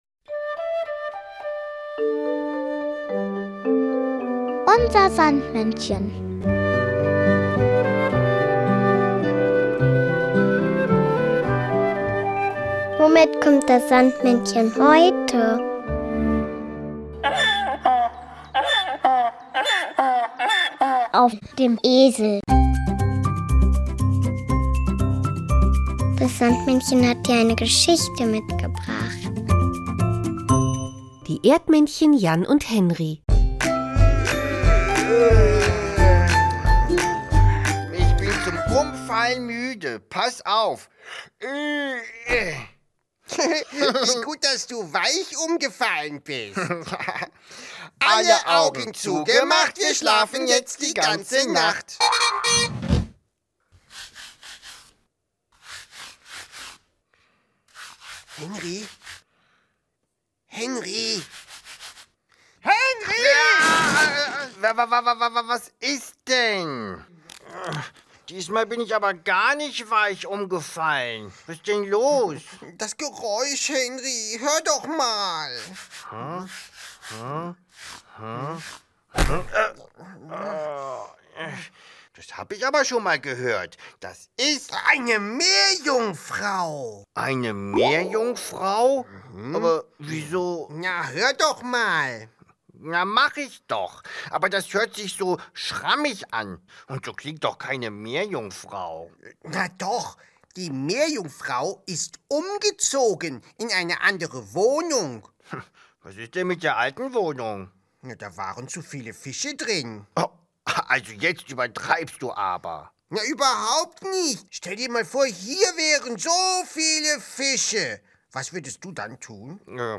das Kinderlied